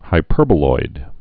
(hi-pûrbə-loid)